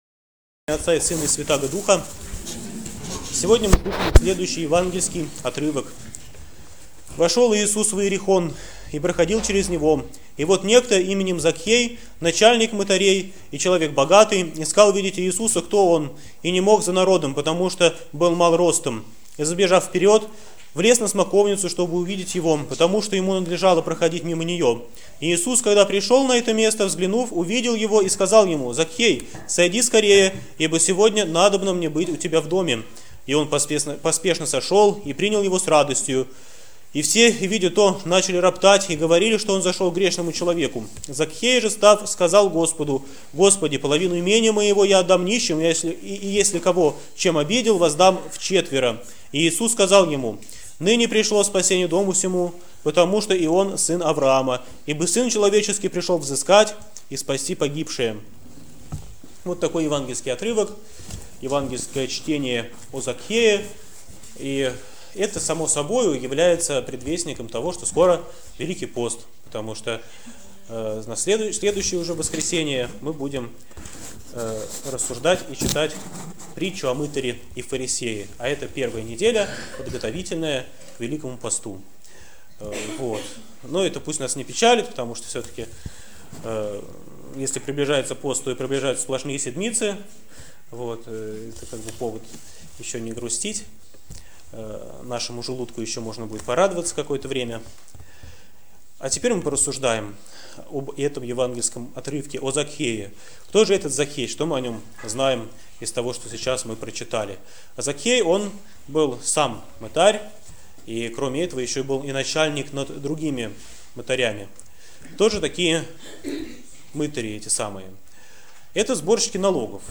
БОГОЛЮБСКИЙ ХРАМ ПОСЕЛОК ДУБРОВСКИЙ
Проповедь в неделю о Закхее 2014